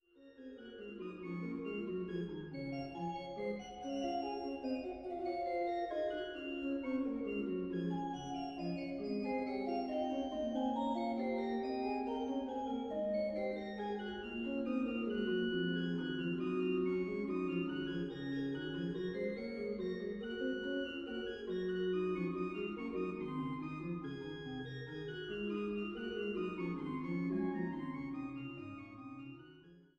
an der größten historischen Orgel des Rheinlandes